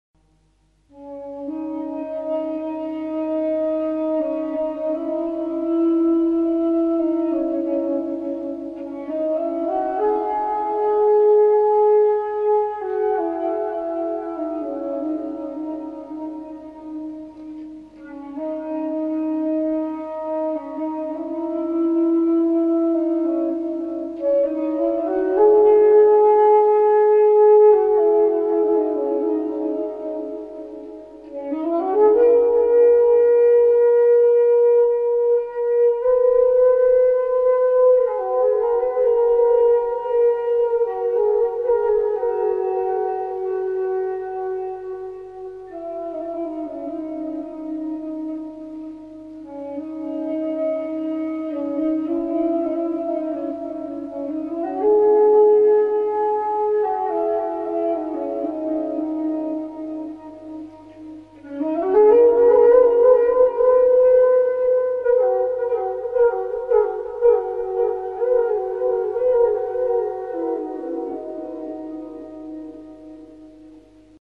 მუსიკა მედიტაციისთვის
ფლეიტა